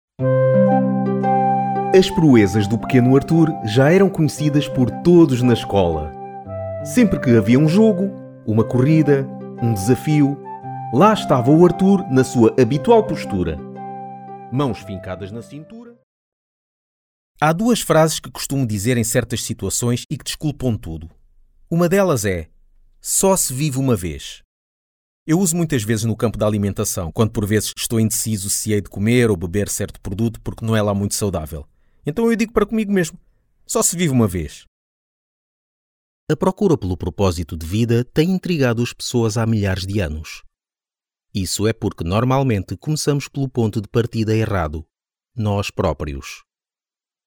Audiobooks
Also, I can do many voice tones: calm, energetic, friendly, aggressive, informative, funny, and many more.
If you want a versatile Portuguese male voice, contact me.
Microphone: Rode NT-1A large diaphragm